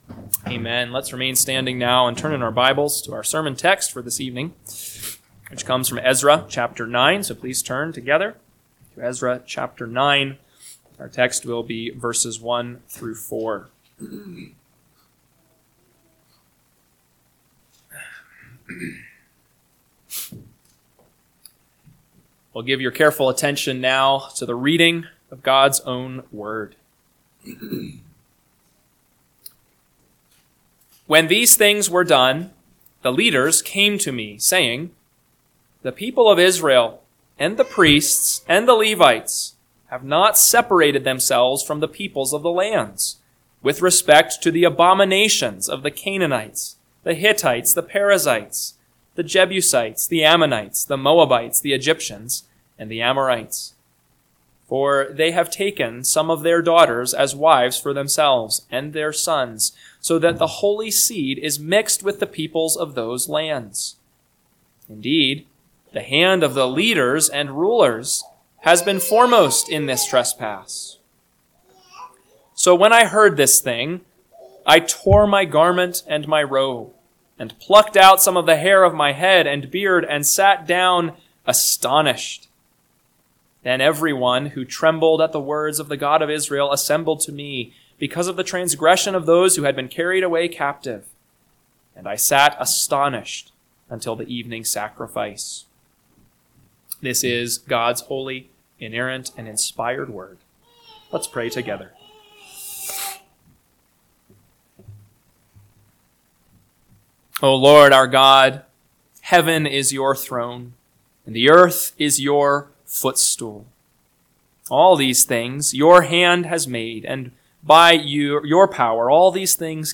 PM Sermon – 5/18/2025 – Ezra 9:1-4 – Northwoods Sermons